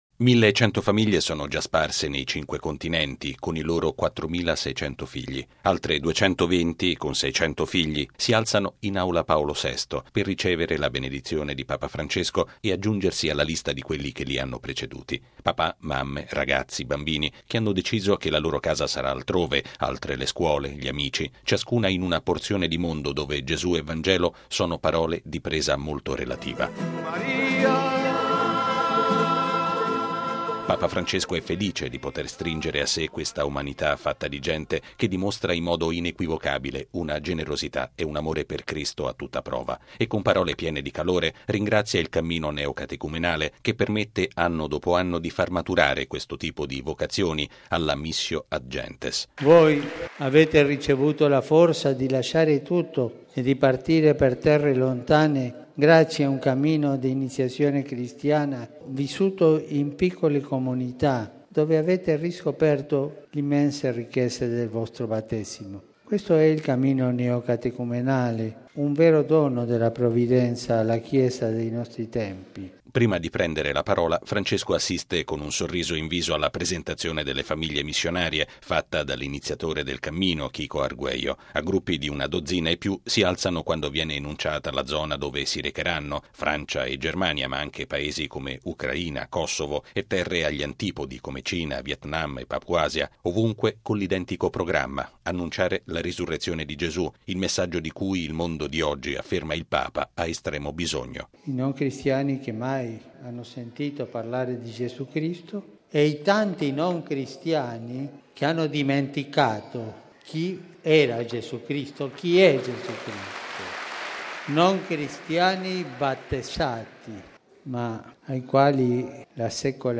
Scrosci di applausi sottolineano quasi ogni pensiero di Francesco, che aveva iniziato con un attestato di stima bagnato poco dopo da una vigorosa approvazione: